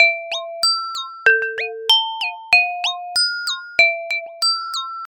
Either A Children's Ringtone Or An Intro To A Hot Rap Song.